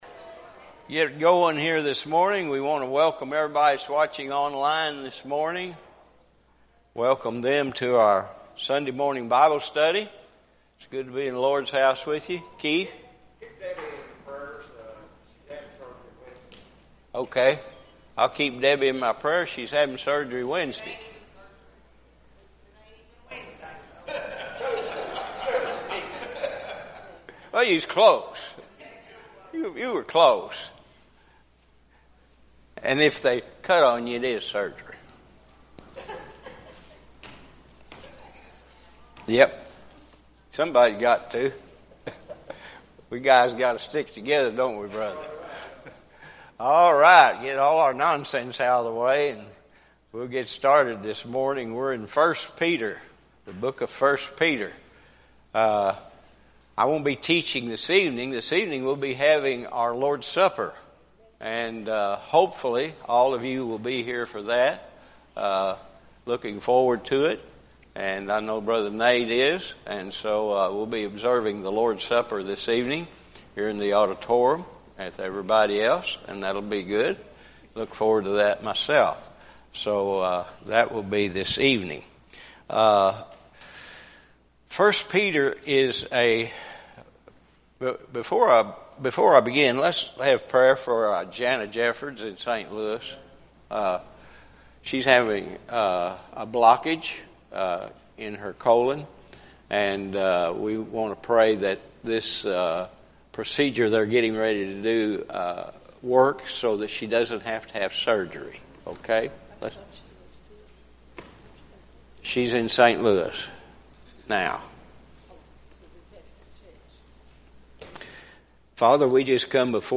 Sermons | Waldo Baptist Church